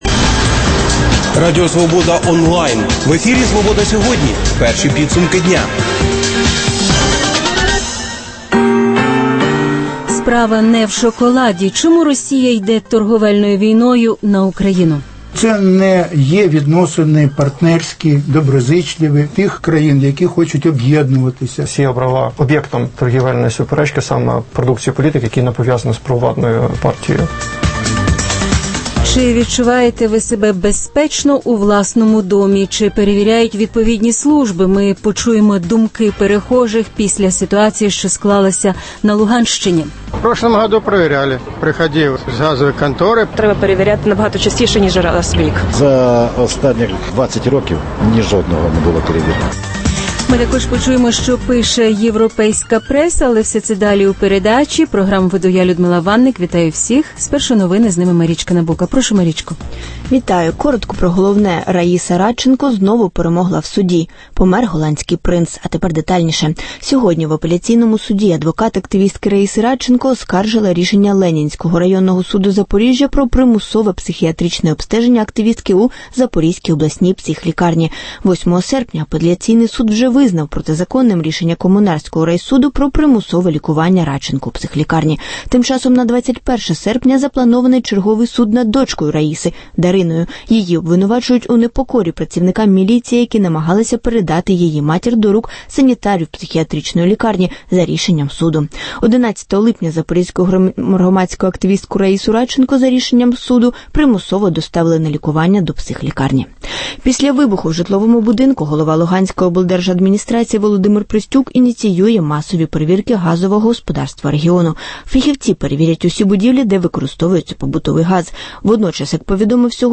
Почуємо думки перехожих